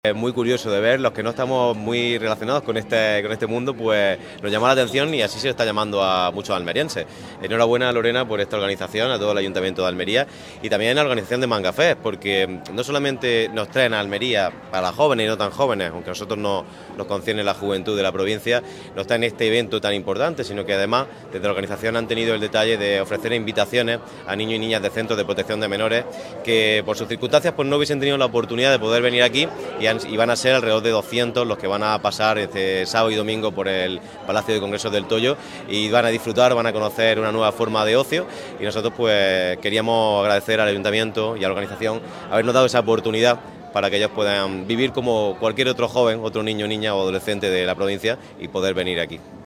FRANCISCO-BELLIDO-DELEGADO-INCLUSION-SOCIAL-Y-JUVENTUD.mp3